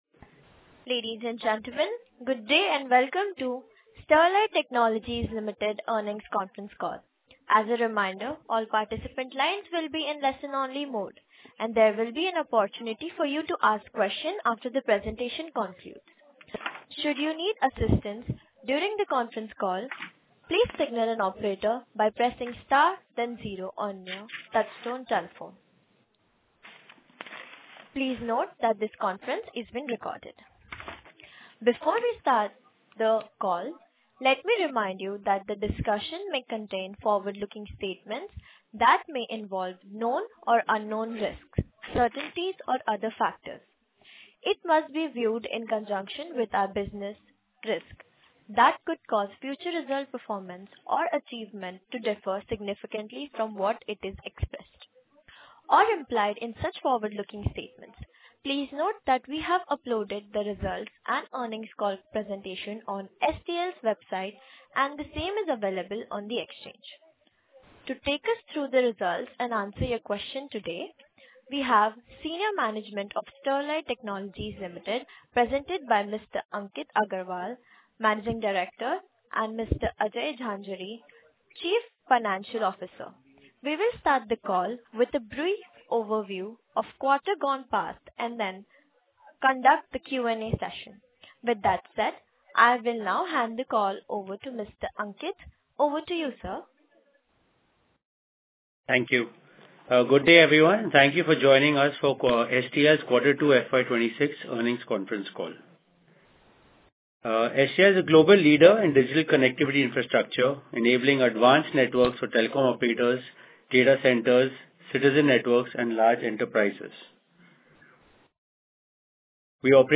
STL-Q2FY26-Earnings-call-audio.mp3